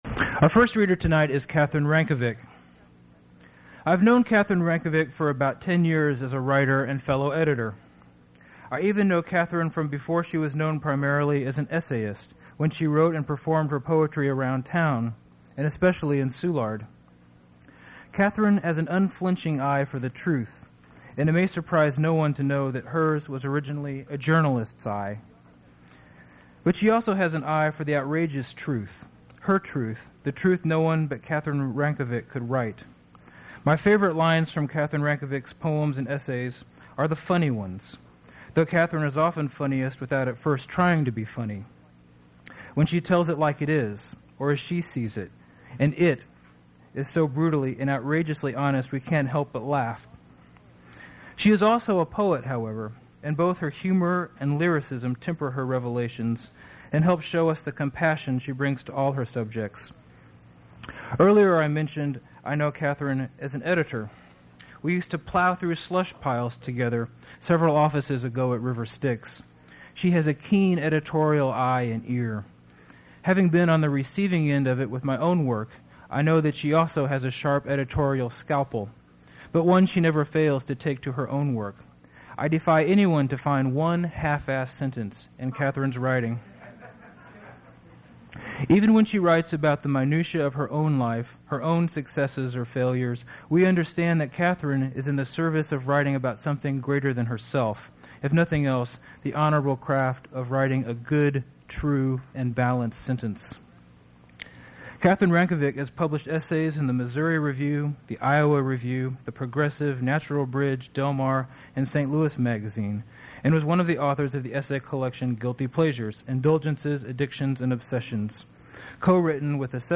Prose reading
prose reading at Duff's Restaurant
original audio cassette. Language English Identifier CASS.873 Series River Styx at Duff's River Styx Archive (MSS127), 1973-2001 Note Cut irrelevant remarks at beginning.